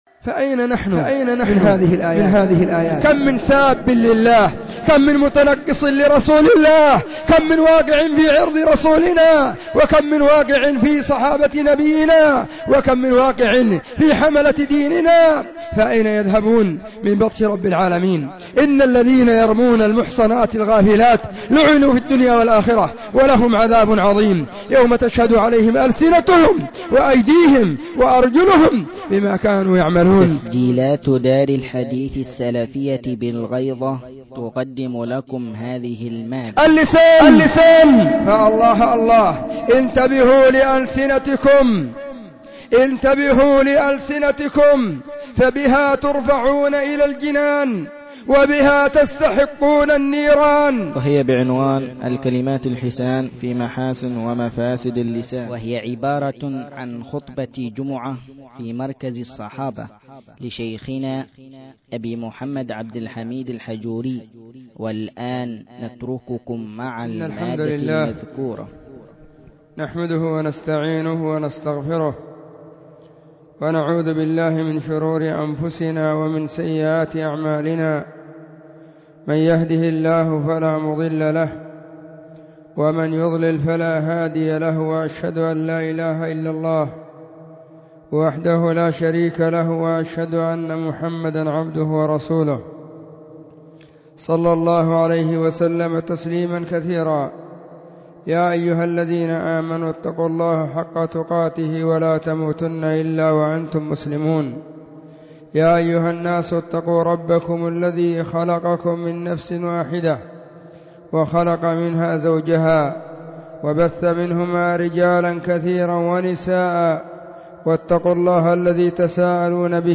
خطبة جمعة بعنوان : *🌱الكلمات الحسان في محاسن ومفاسد اللسان🌱*
📢 وكانت – في – مسجد – الصحابة – بالغيضة- محافظة – المهرة – اليمن.